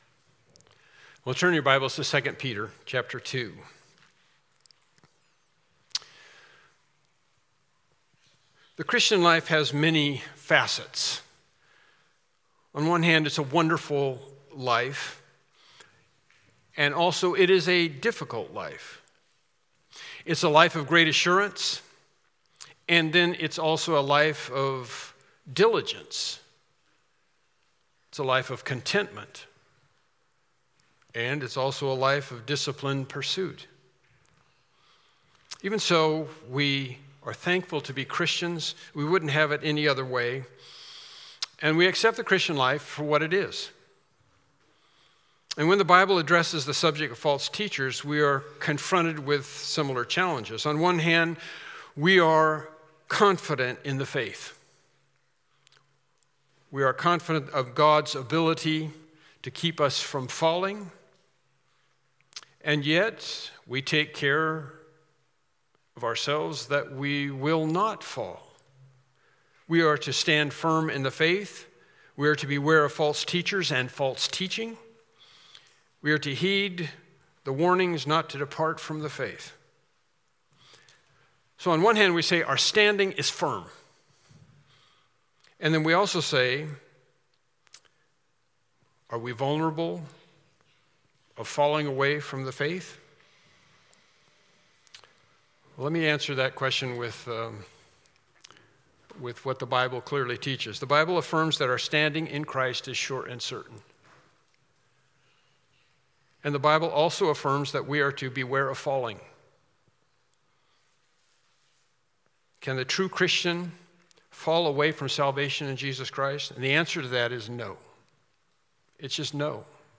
2 Peter 2:20-22 Service Type: Morning Worship Service Topics: False Teachers